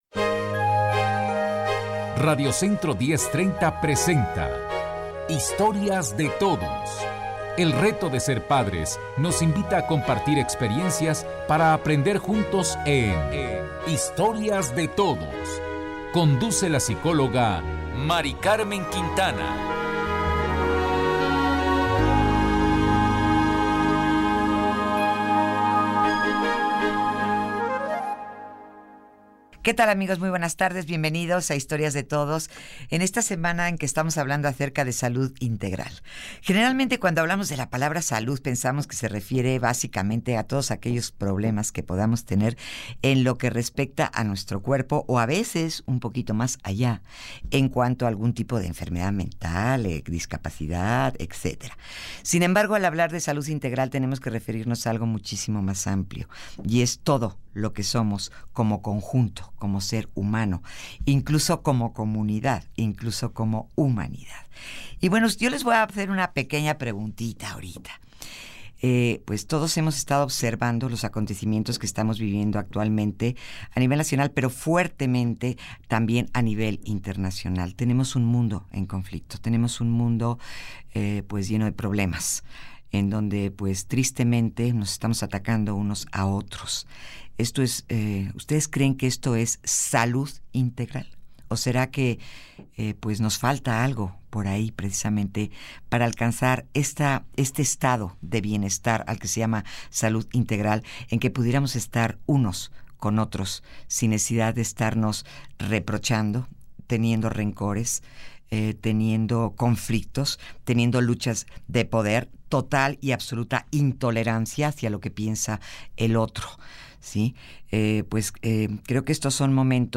Entrevista en Grupo Radio Centro | Power Leadership Center
entrevista_rc.mp3